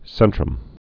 (sĕntrəm)